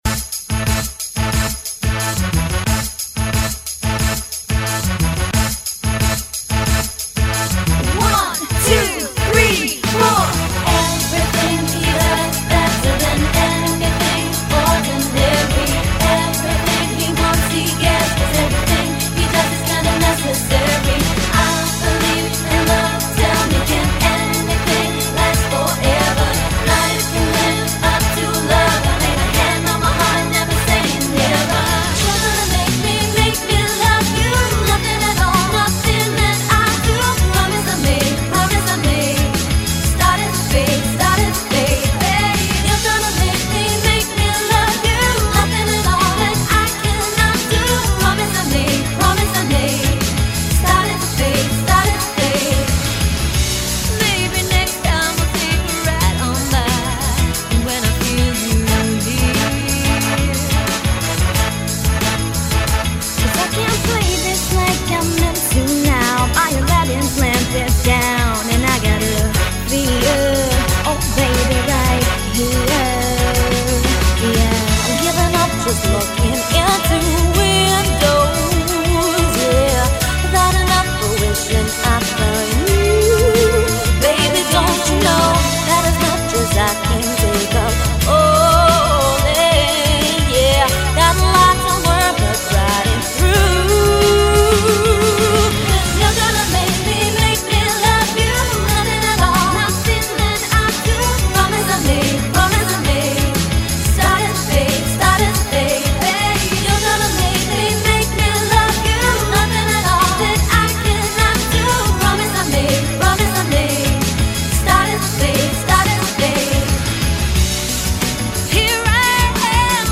featuring 5 stunning girl and 100% live vocals